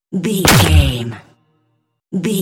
Dramatic hit deep wood
Sound Effects
Atonal
heavy
intense
dark
aggressive